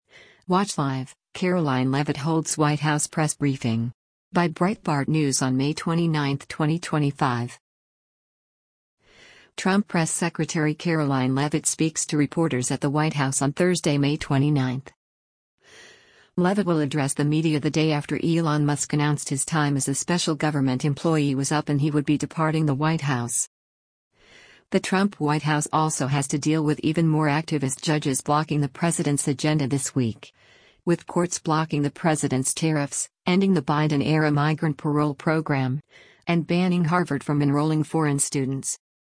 Trump Press Secretary Karoline Leavitt speaks to reporters at the White House on Thursday, May 29.